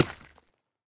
Minecraft Version Minecraft Version snapshot Latest Release | Latest Snapshot snapshot / assets / minecraft / sounds / block / deepslate / break3.ogg Compare With Compare With Latest Release | Latest Snapshot
break3.ogg